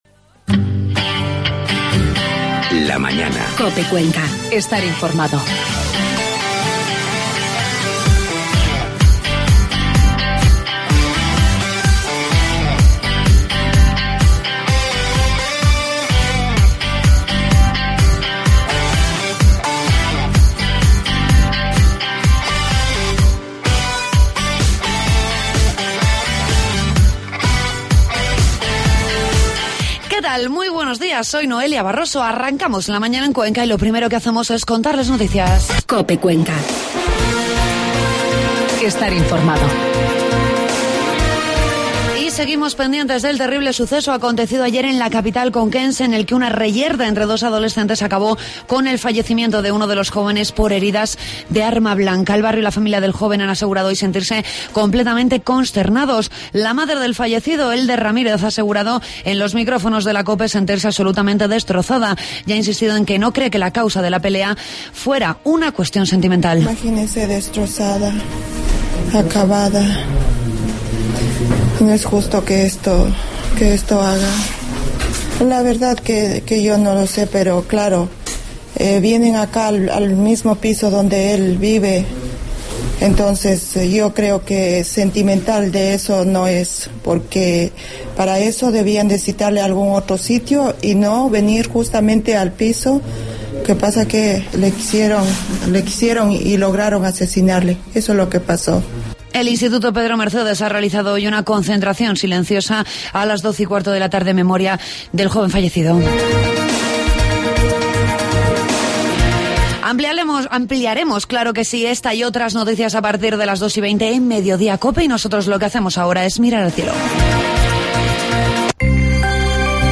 Entrevistamos a la diputada nacional, María Jesús Bonilla, con la que analizamos las cifras del paro.